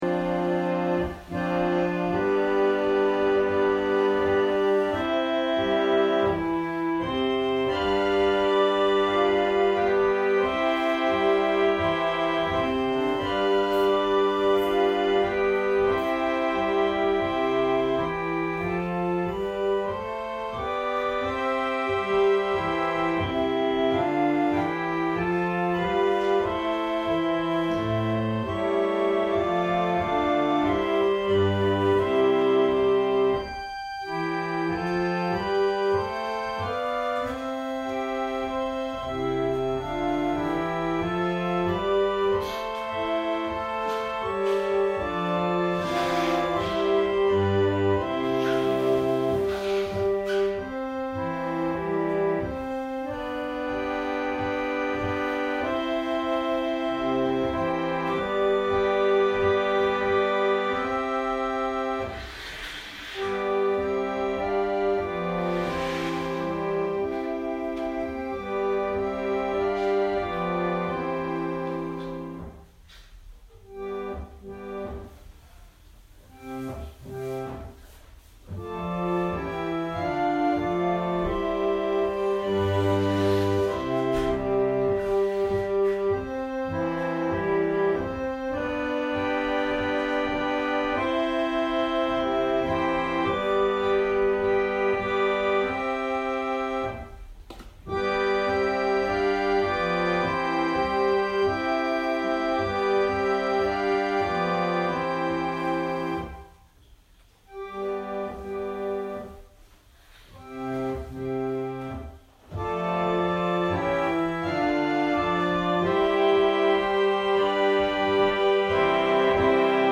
2019年11月10日朝の礼拝「ペリシテとの戦い 블레셋과의 전투」せんげん台教会
説教アーカイブ。